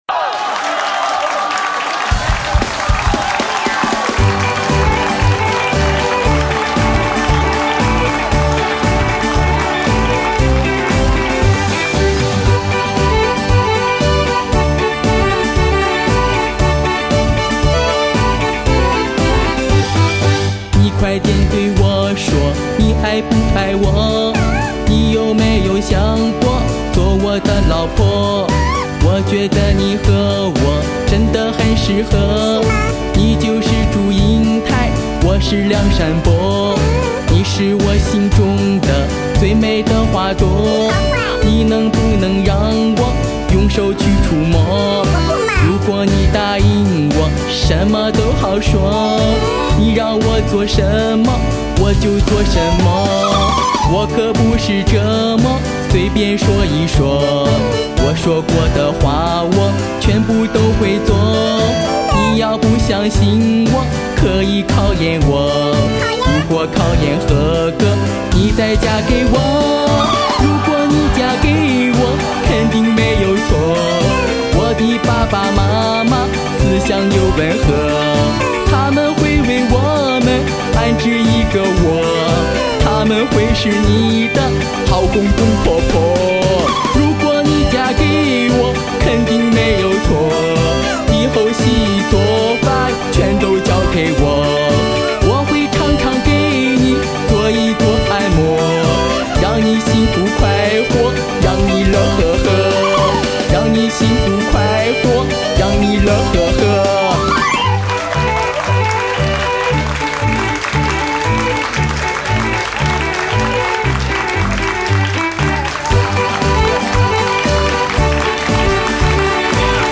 [28/12/2008]音乐相声__嫁给我没有错